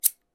Tijera recortando un papel 2
tijera
Sonidos: Acciones humanas
Sonidos: Oficina